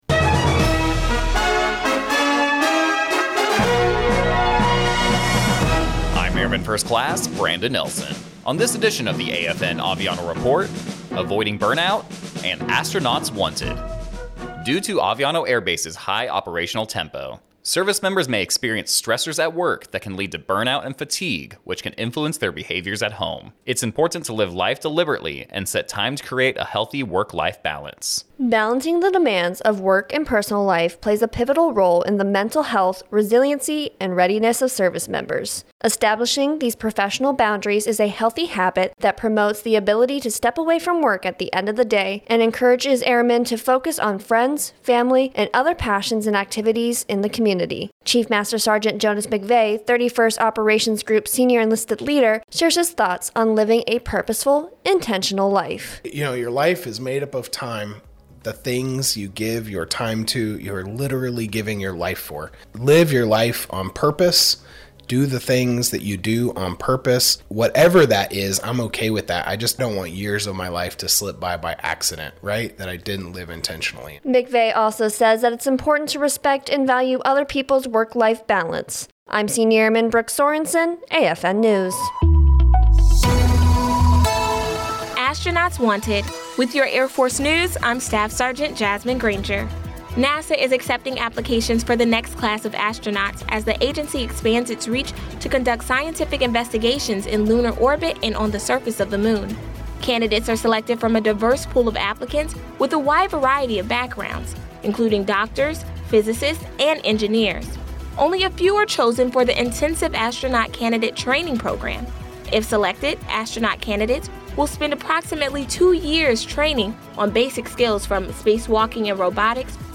American Forces Network (AFN) Aviano radio news reports on Aviano Air Base’s high operational tempo and the influence it plays on work-life balance.